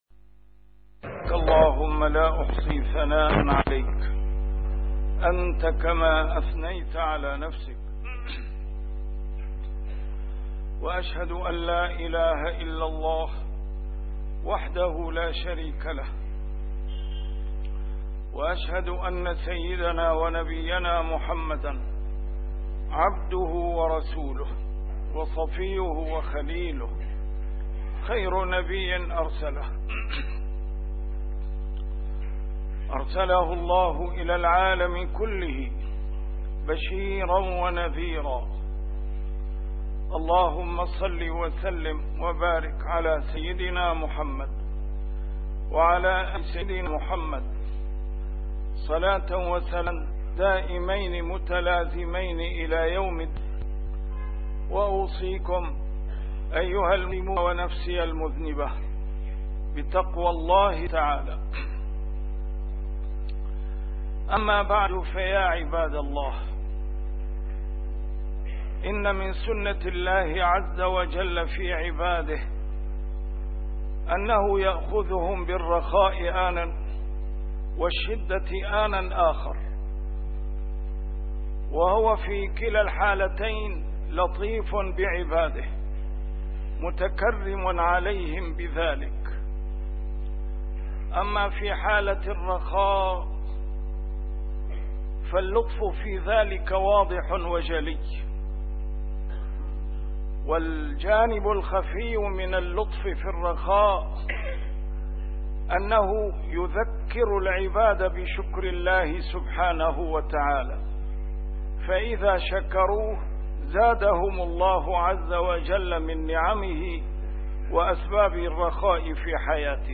A MARTYR SCHOLAR: IMAM MUHAMMAD SAEED RAMADAN AL-BOUTI - الخطب - رسالة إلى أصحاب الأعشاش